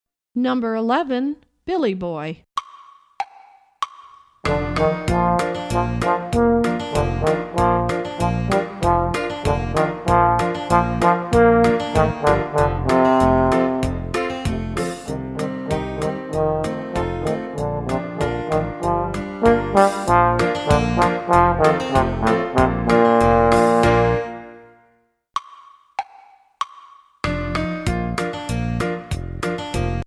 Voicing: Bassoon